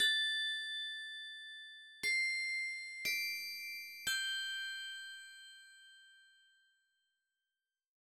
28 Bells PT3.wav